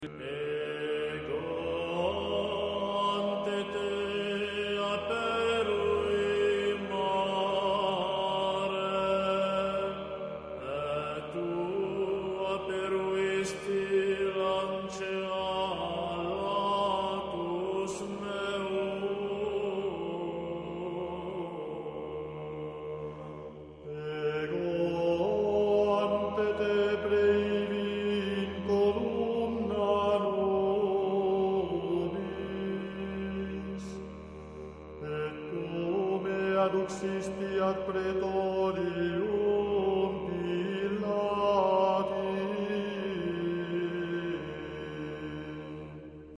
Esecuzione sulla scorta di codici del rito romano antico.
La seconda parte ribadisce gli stessi concetti sopra espressi ma suddivisi in nove improperia (cantati dai soli su modello salmodico) intercalati dal coro che ripete ogni volta i primi versi con cui aveva esordito nella prima parte: "Popule meus, quid feci tibi? Aut in quo contristavi te? Responde mihi!":